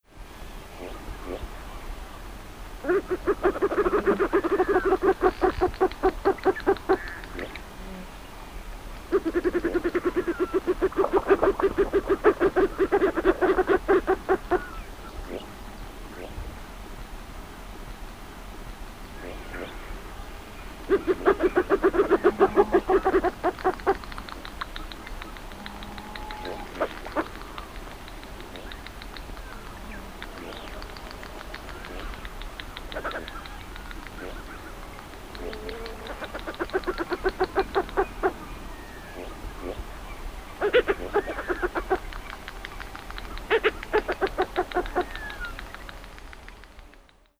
Heteronetta atricapilla - Pato cabeza negra
patocabezanegra.wav